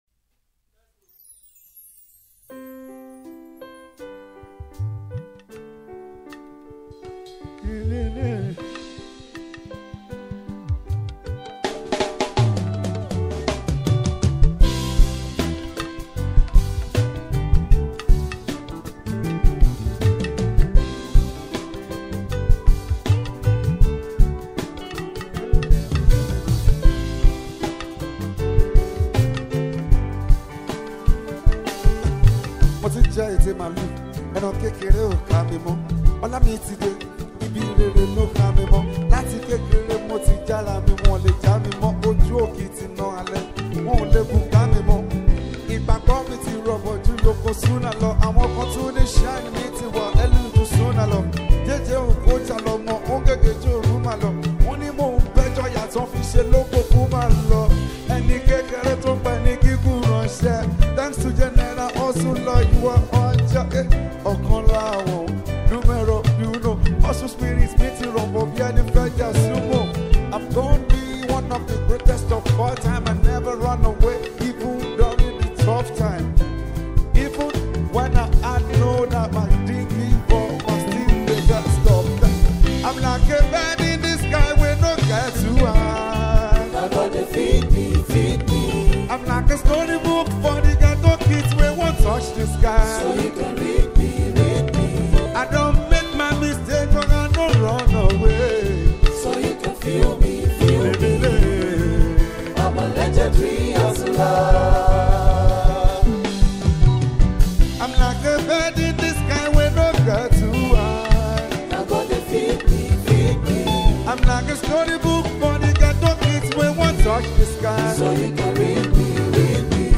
especially people with so much love for Yoruba Fuji Music.